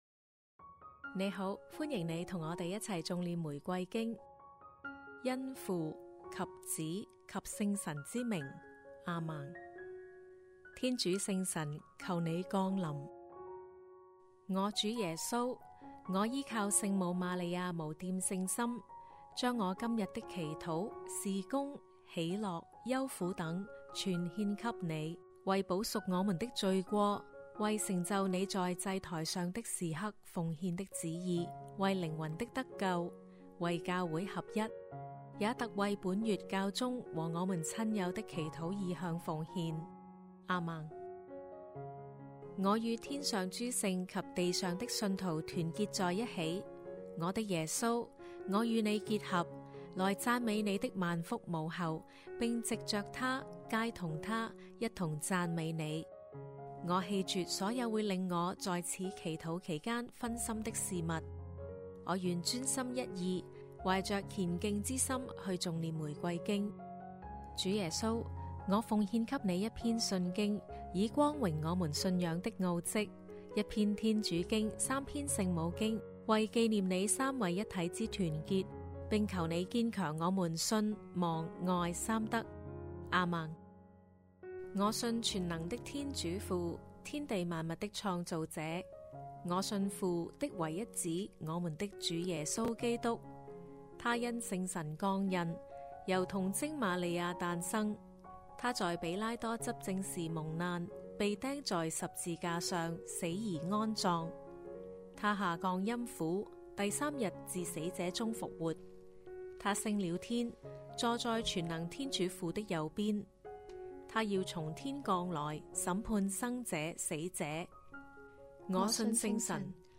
童聲齊頌玫瑰經：光明五端*